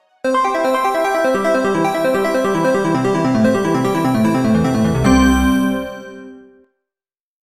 Звуки для игр
Продолжительный звук поражения Game Over для игры